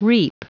Prononciation du mot reap en anglais (fichier audio)
Prononciation du mot : reap